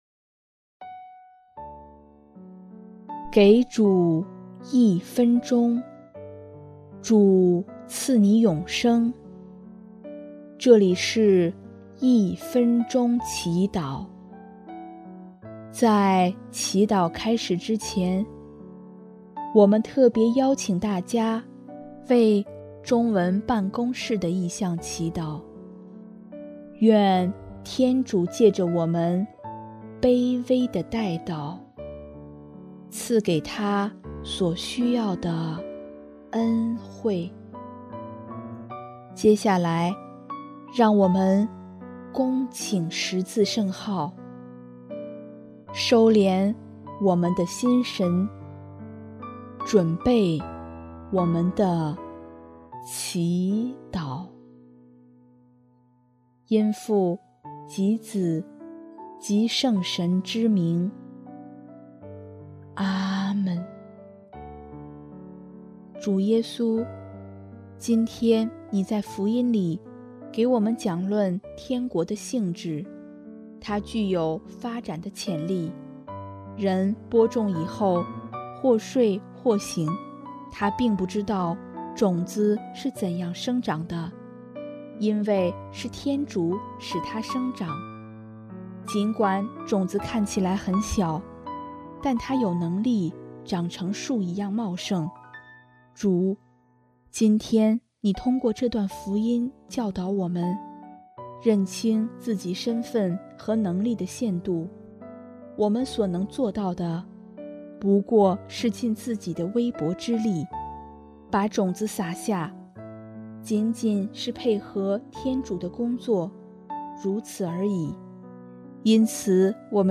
音乐：第二届华语圣歌大赛参赛歌曲《相信》（中文办公室:祈求圣神帮助办公室的发展方向，给更多的人带去福音）